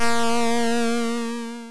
synth3.wav